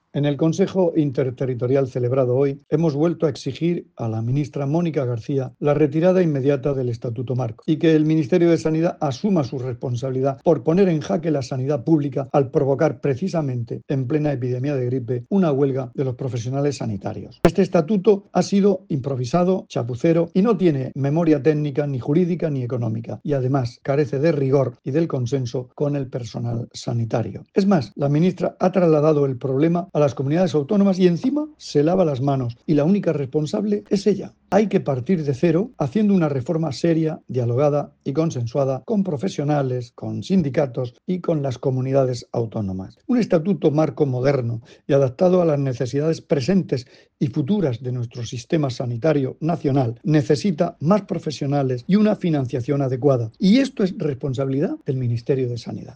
Sonido/ Declaraciones del consejero de Salud, Juan José Pedreño, sobre el Consejo Interterritorial del Sistema Nacional de Salud celebrado hoy.